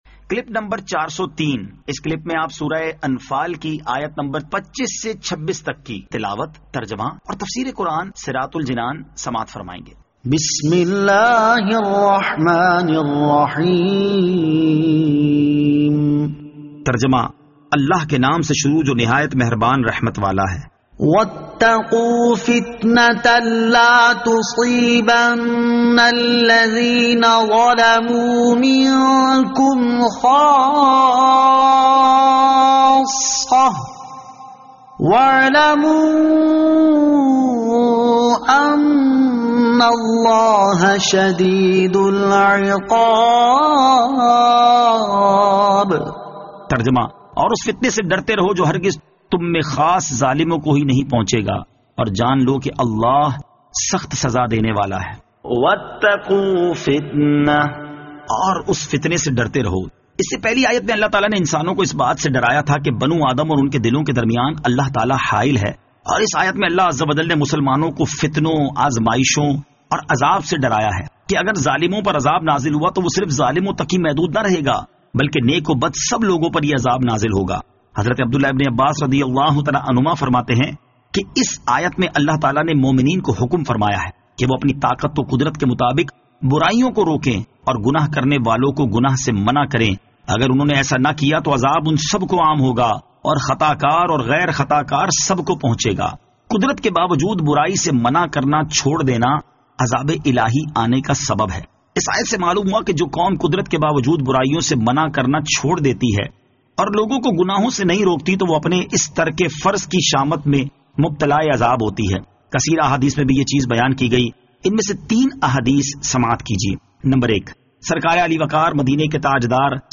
Surah Al-Anfal Ayat 25 To 26 Tilawat , Tarjama , Tafseer